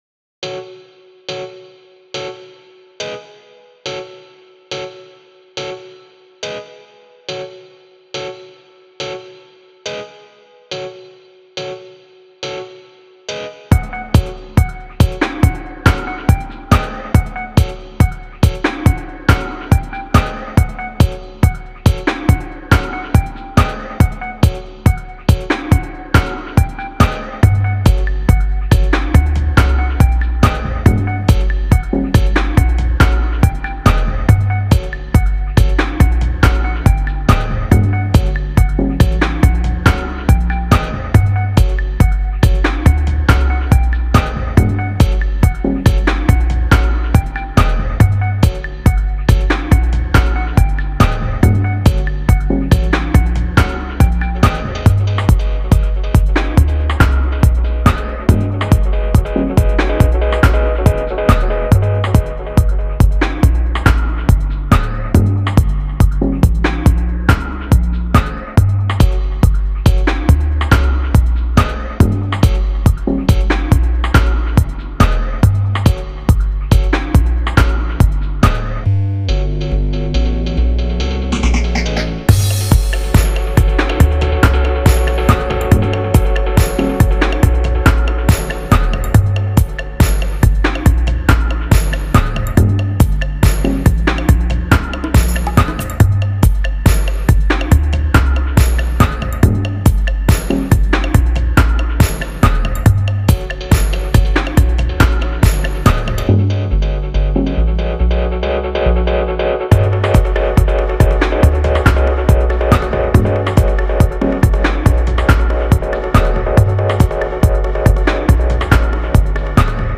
heavyweight Steppas lick